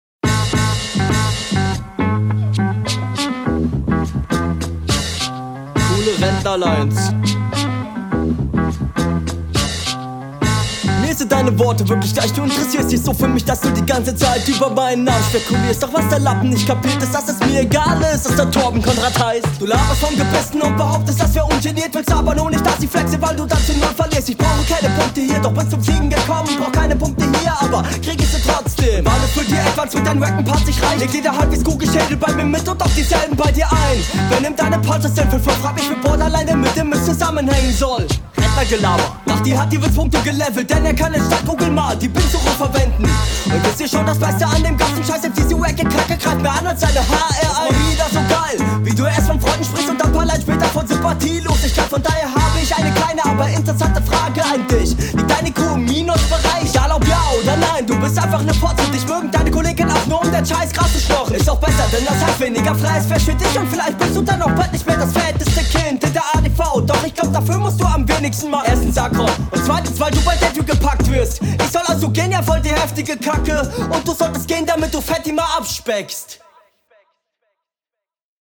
Flow: Verschluckst dich ganz am Anfang etwas, steigst dann aber in den Part mit einem …
Flow: Der Beat harmoniert nicht so gut mit deiner Stimme, was dafür sorgt, dass das …
Flow: deine doppelspur oder es liegt an der mische, aber es ist schwierig dir zu …